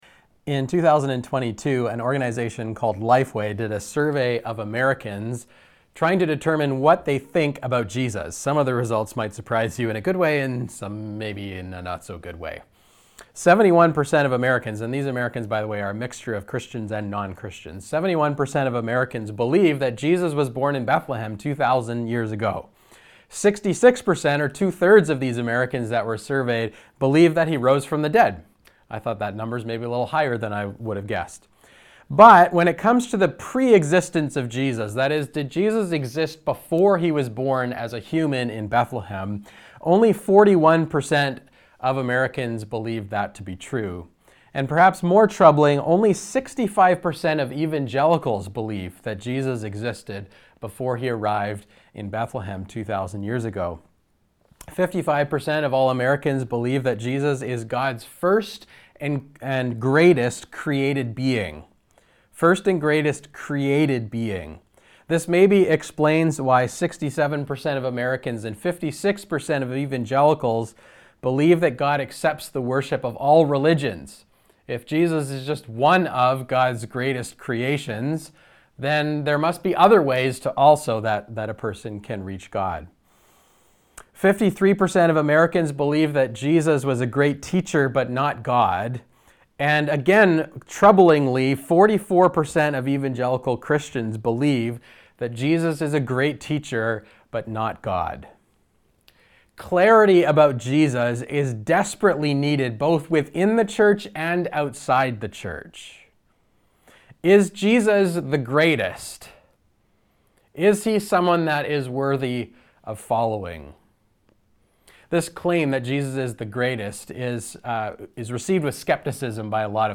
SERMON NOTES God speaks The nature of Jesus The supremacy of Jesus For reflection: Read through the entire book of Hebrews in one sitting.